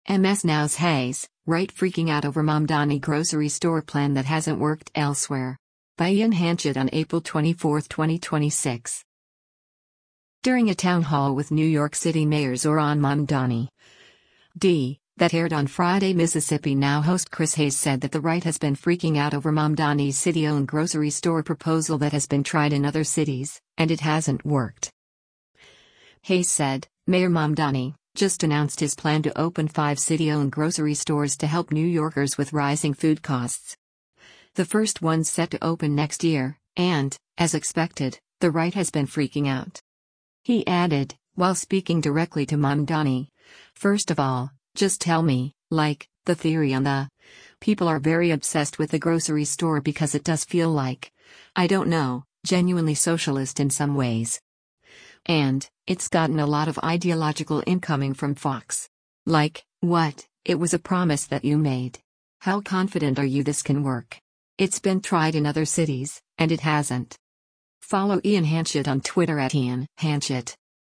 During a town hall with New York City Mayor Zohran Mamdani (D) that aired on Friday, MS NOW host Chris Hayes said that “the right has been freaking out” over Mamdani’s city-owned grocery store proposal that has “been tried in other cities, and it hasn’t” worked.